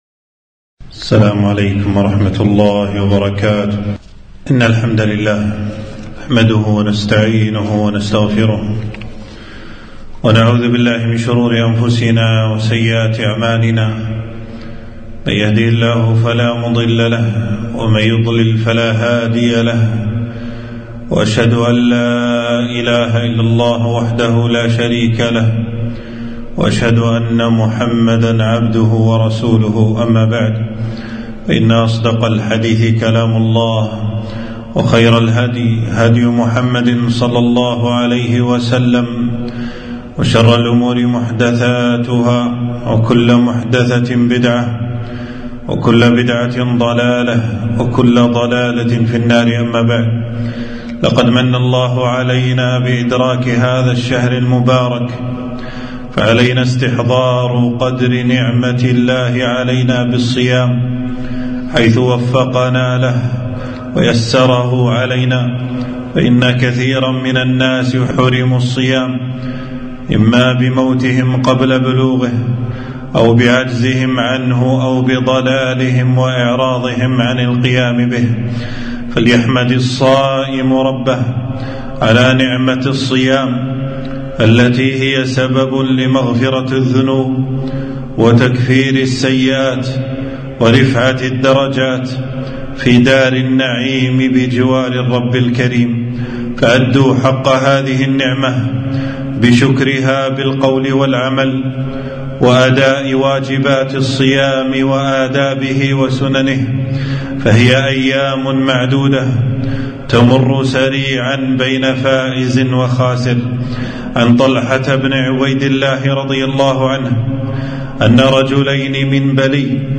خطبة - آداب الصيام وسننه